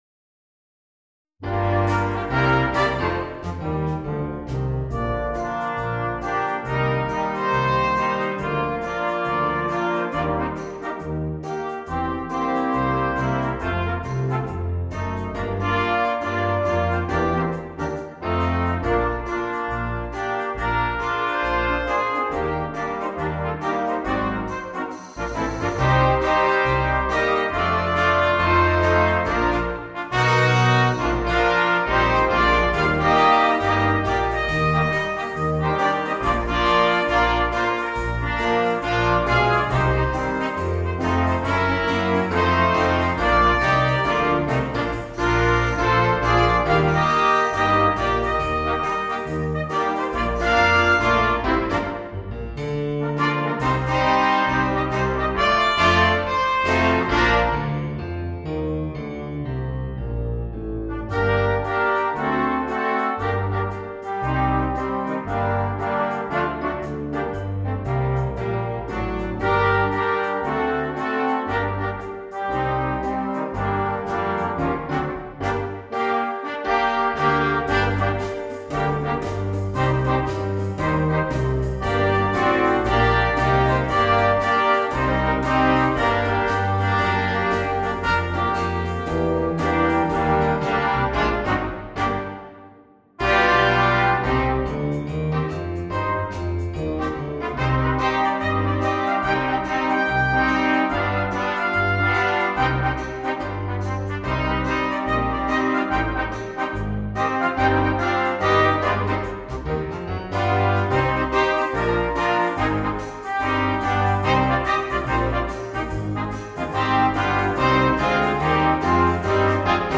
Gattung: für 4- bis 8-stimmiges gemischtes Ensemble
Besetzung: Ensemble gemischt